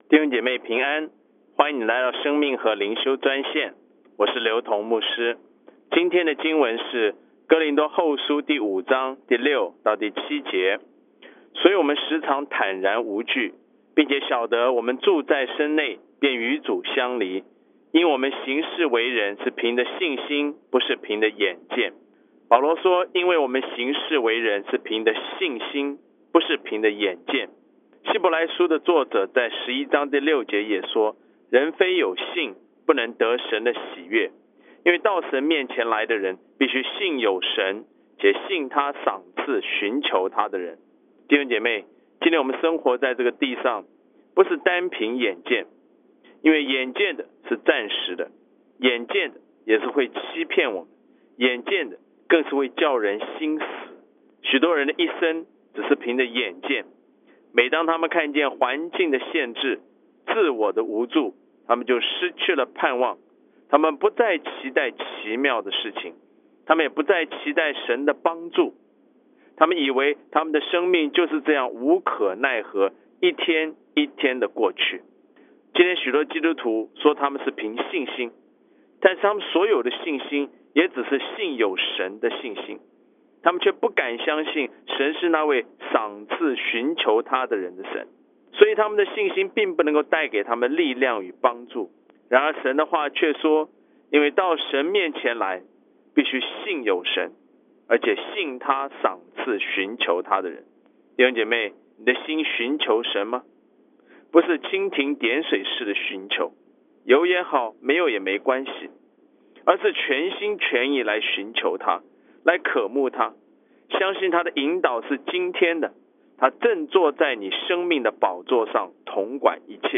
以生活化的口吻带领信徒逐章逐节读经